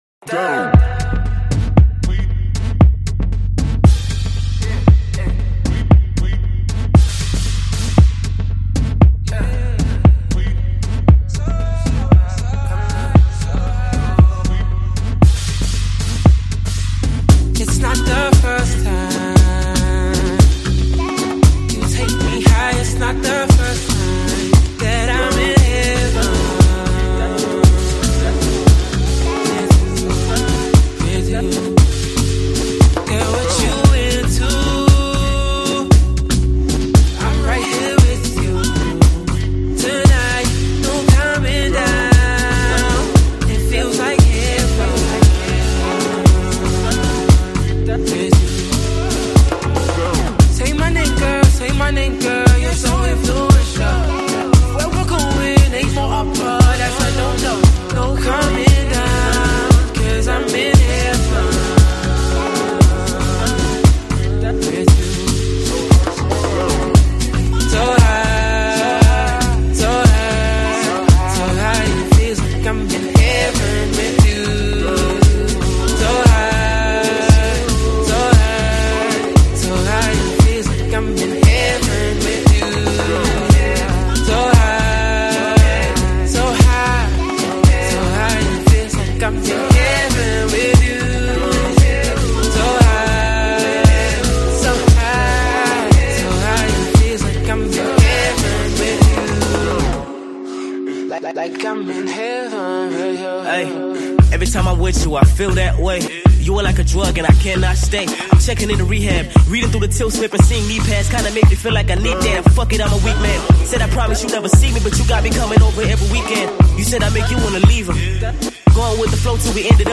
South African rapper
dropped some cool flows on the joint